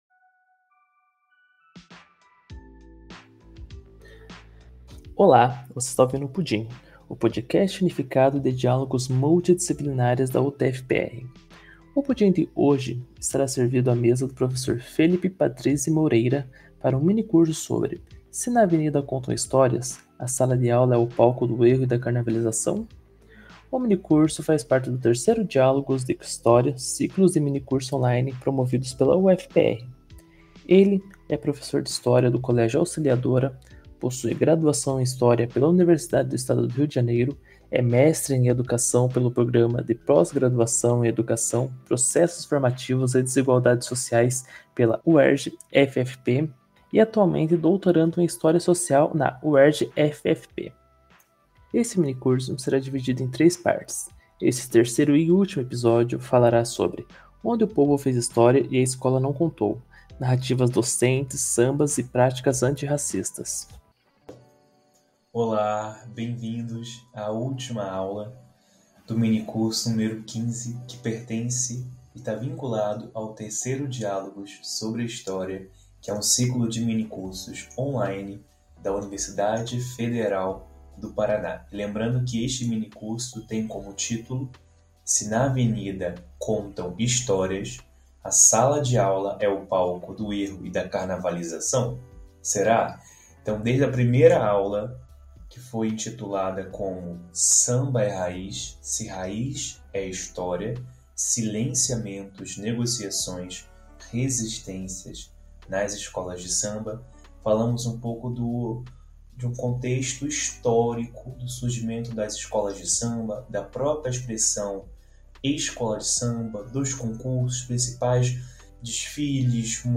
num minicurso dividido em três partes. Neste terceiro e último episódio, veremos a importância de resgatar histórias silenciadas pela educação tradicional, valorizando as narrativas populares e os sambas como instrumento de ensino.